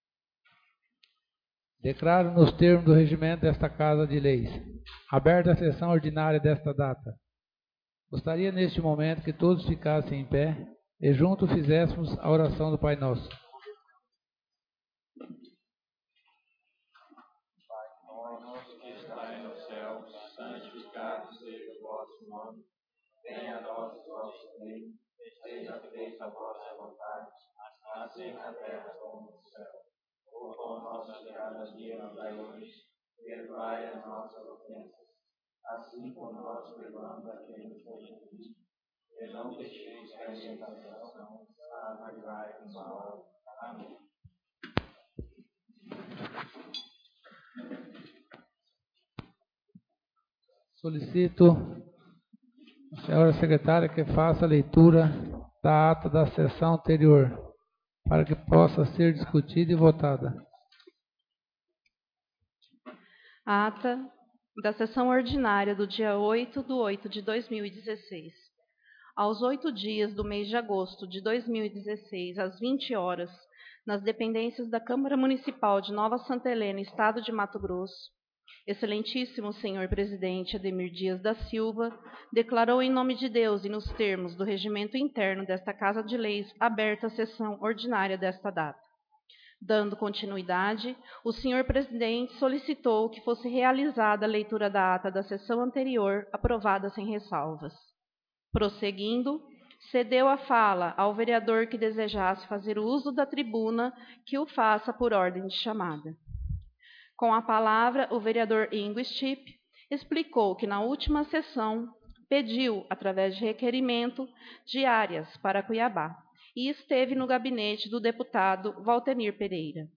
Sessão Ordinária 15/08/2016